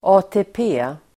Uttal: [a:te:p'e:]